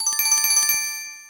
［BGM・SE素材］
sozai_harutoki3_se_suzu-a02.mp3